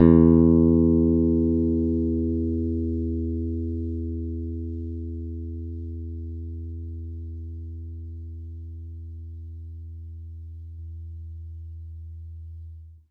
bass-electric
E2.wav